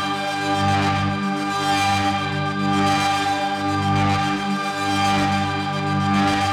Index of /musicradar/dystopian-drone-samples/Tempo Loops/110bpm
DD_TempoDroneB_110-G.wav